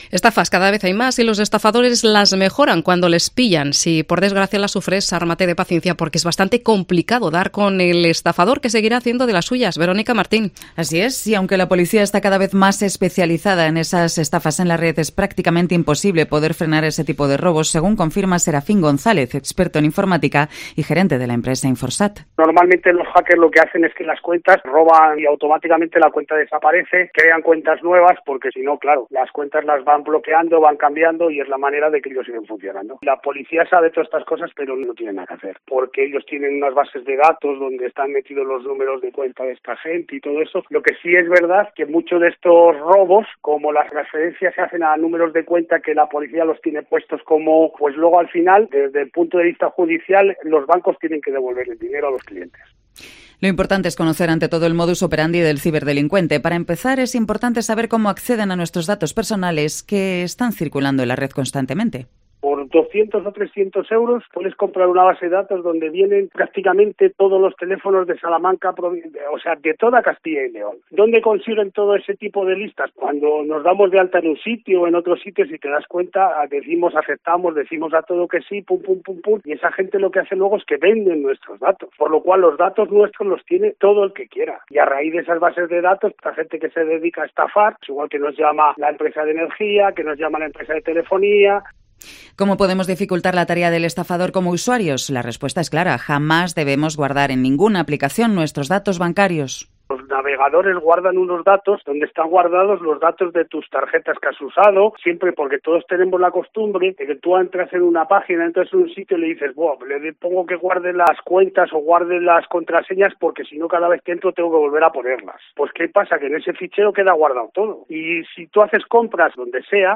COPE Salamanca habla con el experto en informática